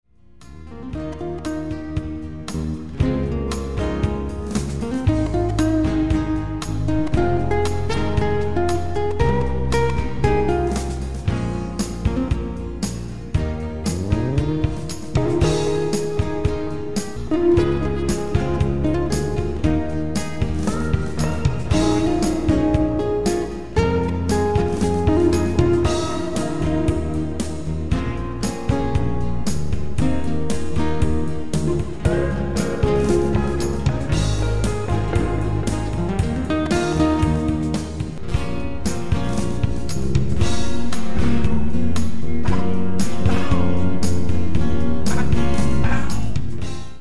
Sie sind zum träumen und entspannen gedacht.
klingt nach zwei amerikanischen Bands, America + Eagles.